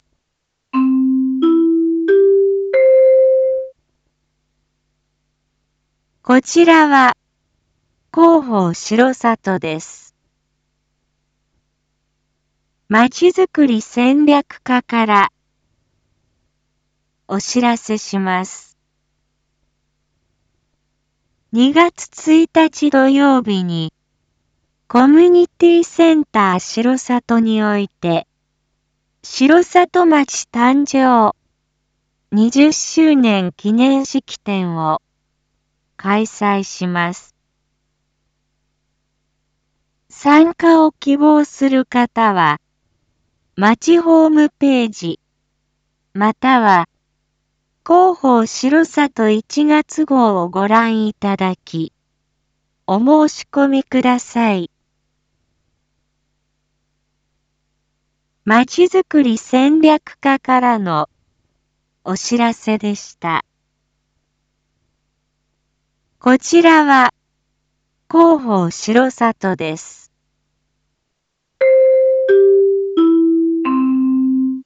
一般放送情報
Back Home 一般放送情報 音声放送 再生 一般放送情報 登録日時：2025-01-08 19:01:16 タイトル：城里町誕生20周年記念式典の開催 インフォメーション：こちらは広報しろさとです。